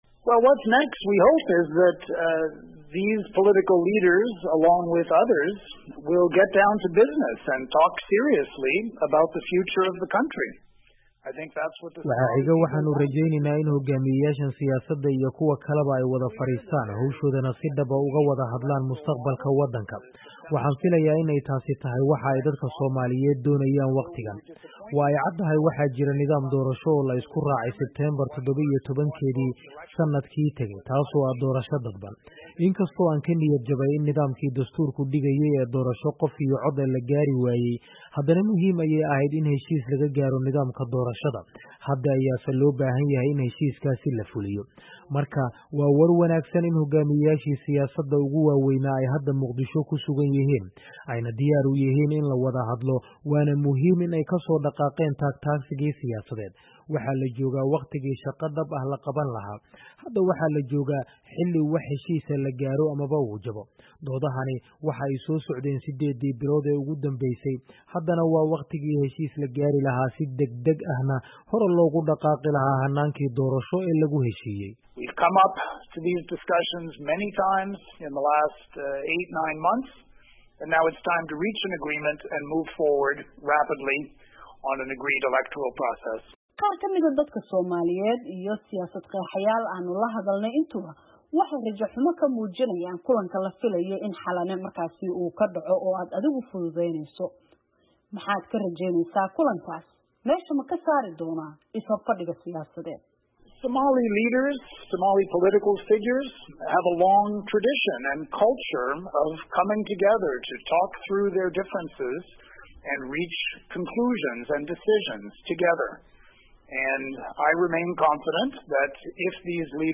Wareysi ku saabsan arrimaha doorashooyinka Soomaaliya oo aan la yeelanay James Swan
Wareysigii James Swan